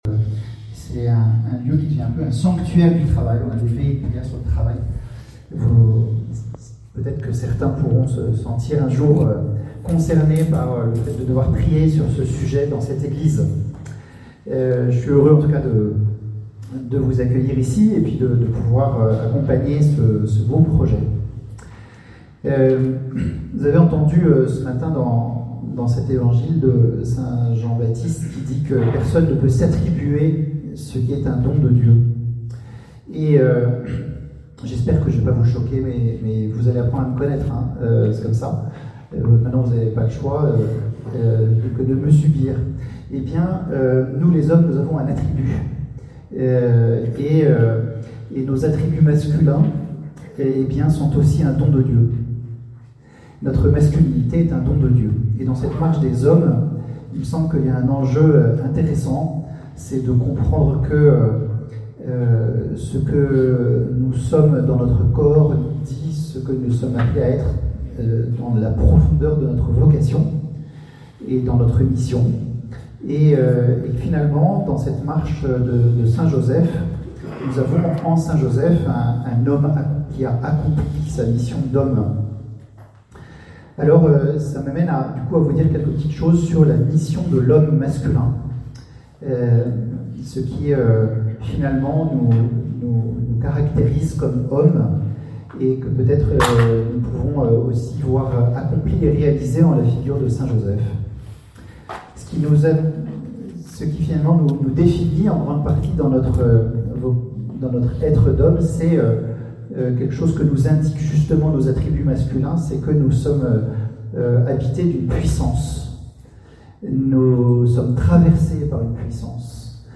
Enseignement
lors de la récollection des chefs de chapitre